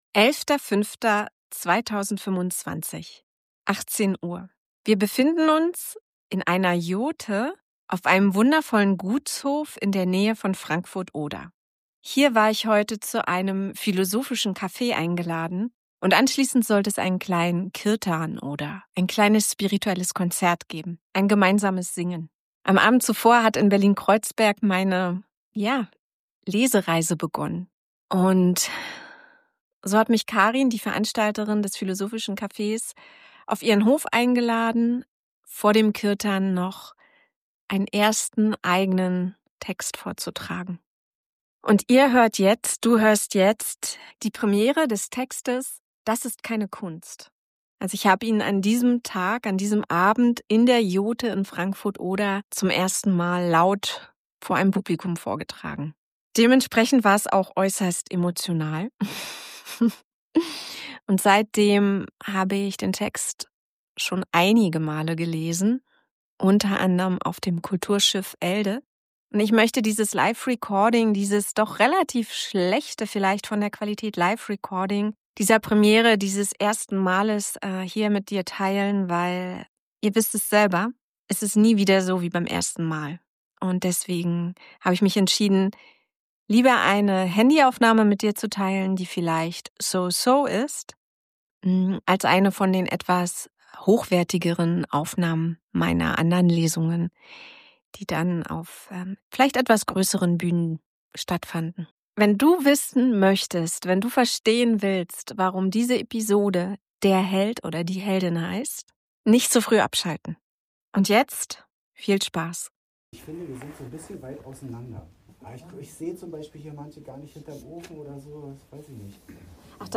Jurtenlesung